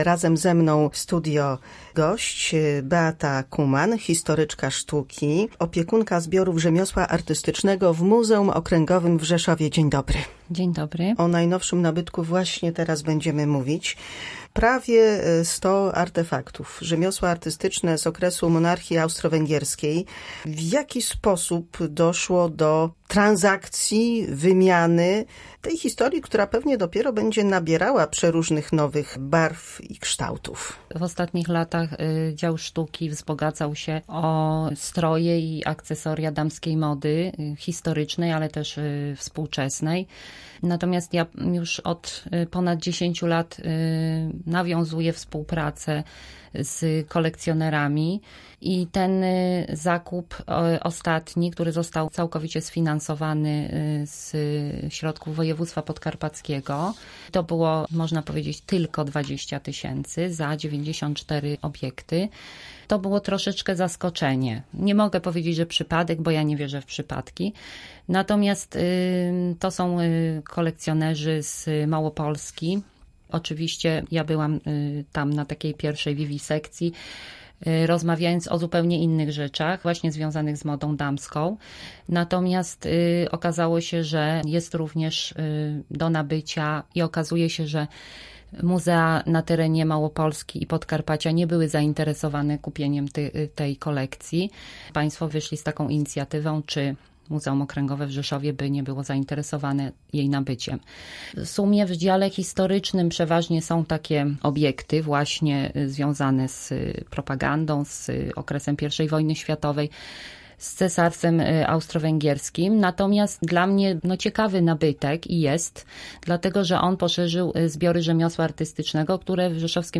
Kubki, filiżanki, przyciski do papieru, patery… prawie sto zabytków z zakresu rzemiosła artystycznego wzbogaciło zbiory Muzeum Okręgowego w Rzeszowie. W audycji rozmowa o początkach, historii kolekcji związanej między innymi z postacią cesarza Franciszka Józefa I, Wilhelma II oraz monarchią austro-węgierską.